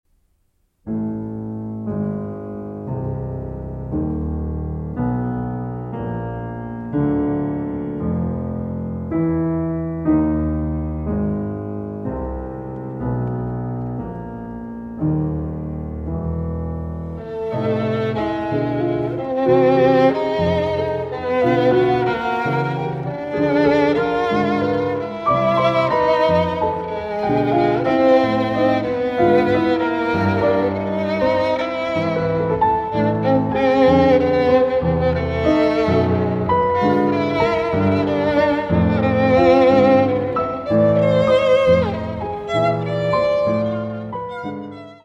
for violin and piano